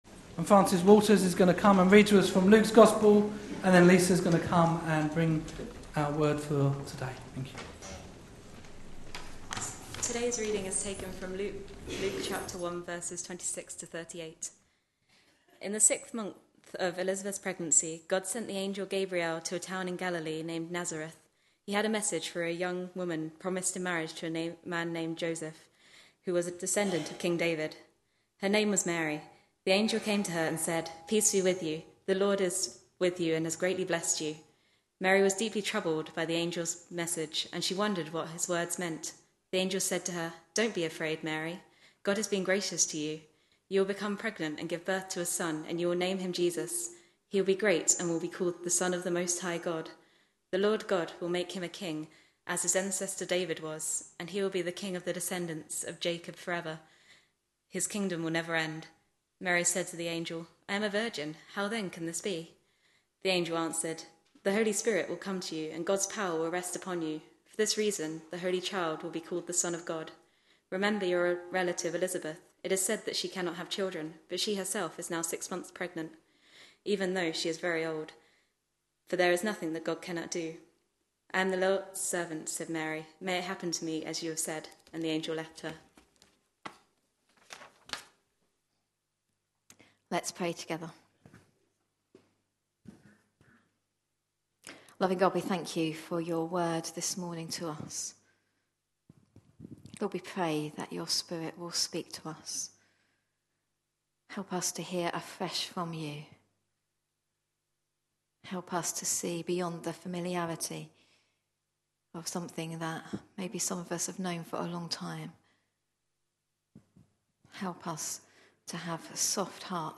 A sermon preached on 30th November, 2014, as part of our Advent 2014. series.